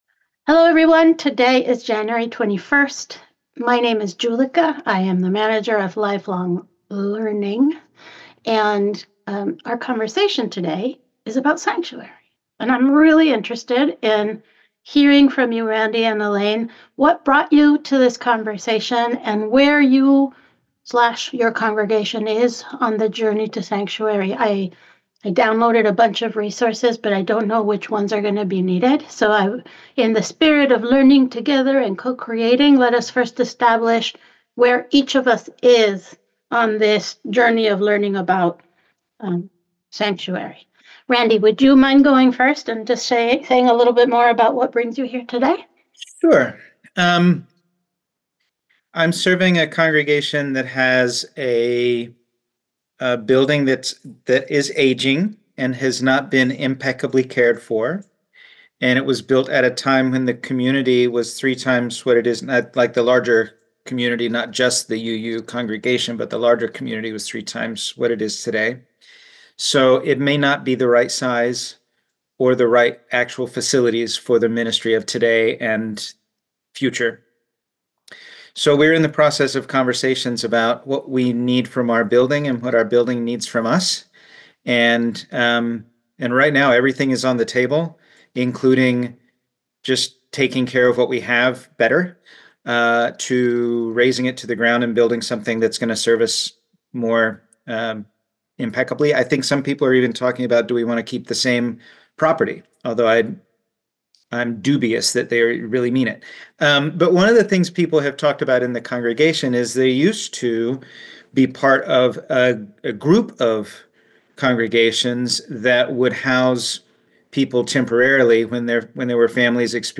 Today’s offering is a vitally important conversation on congregations as sanctuaries.